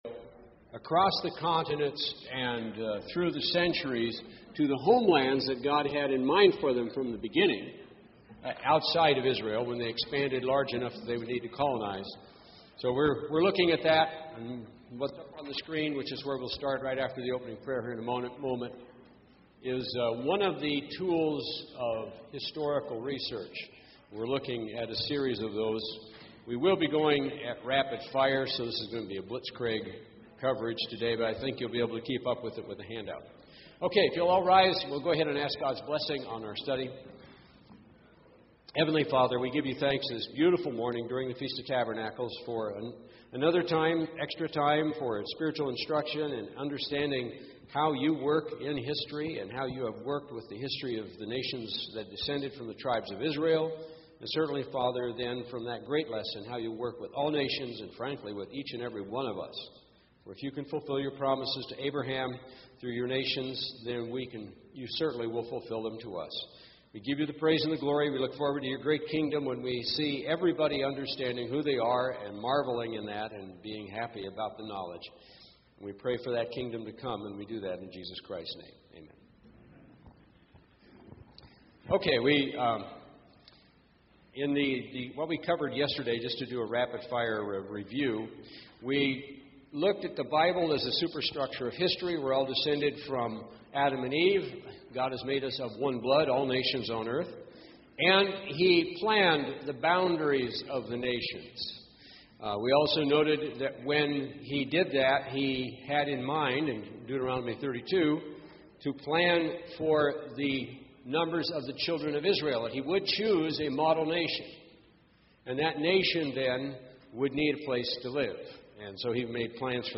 This sermon was given at the Gatlinburg, Tennessee 2013 Feast site.